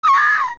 • During the final battle with Ganon in Ocarina of Time and its remake Ocarina of Time 3D, whenever Link ends up hit by one of Ganon's attacks, Princess Zelda can be heard screaming
in horror.
OOT_AdultZelda_Scream2.wav